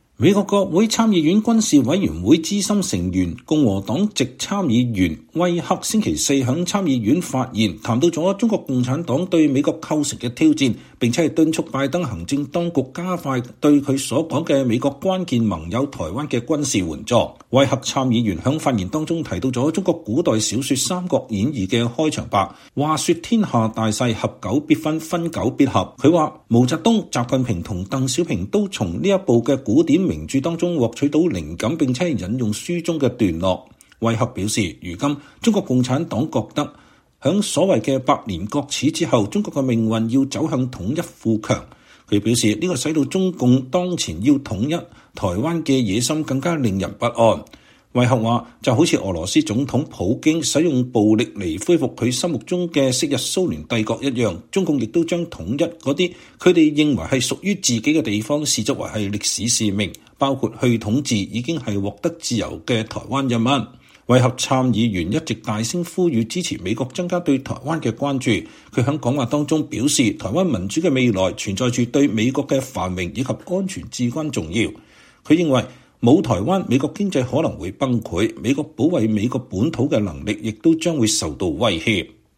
美國國會參議院軍事委員會資深成員、共和黨籍參議員羅傑·威克（Roger Wicker）週四（2月9日）在參議院發言，談到中國共產黨對美國構成的挑戰，並敦促拜登行政當局加快對他所說的美國“關鍵盟友”台灣的軍事援助。